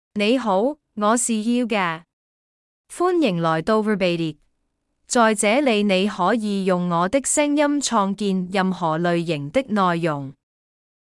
HiuGaaiFemale Chinese AI voice
HiuGaai is a female AI voice for Chinese (Cantonese, Traditional).
Voice sample
Listen to HiuGaai's female Chinese voice.
Female